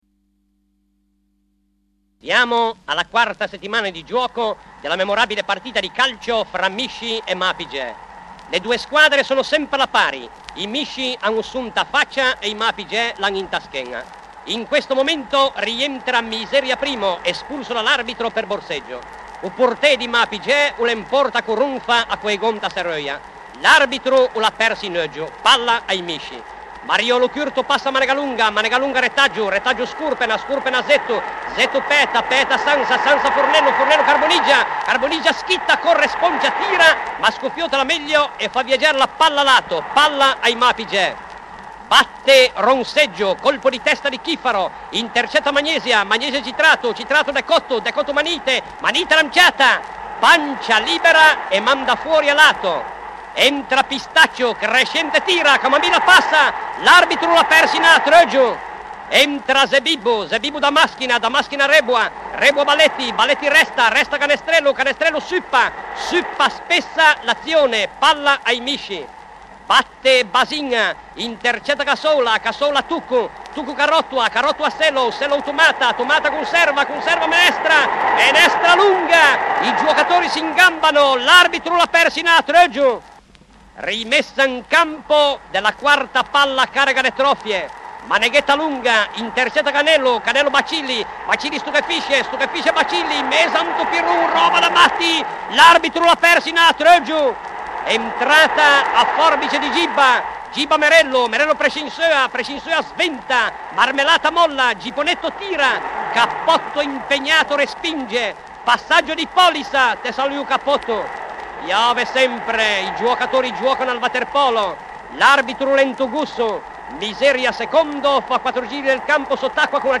FOLKLORE ITALIANO - LIGURIA - RACCOLTA DI CANTI TRADIZIONALI E SCENE COMICHE